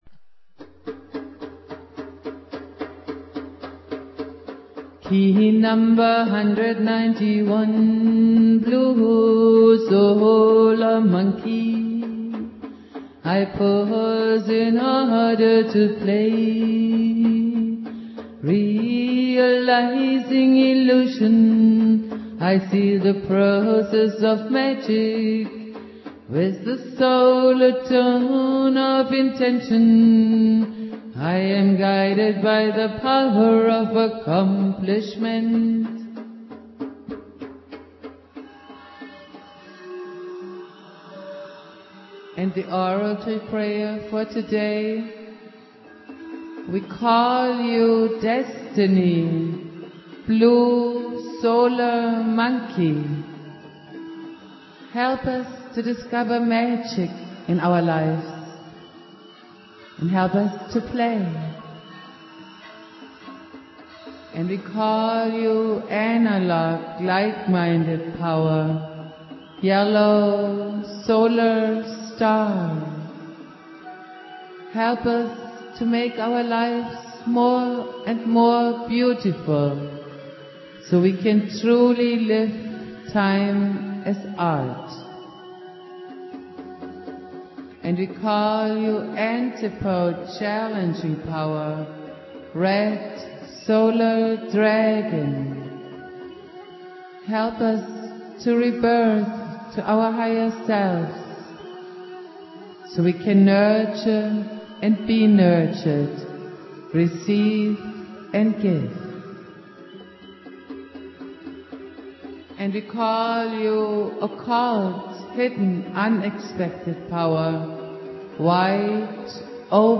Prayer
Jose's spirit and teachings go on Jose Argüelles playing flute.
Jose's spirit and teachings go on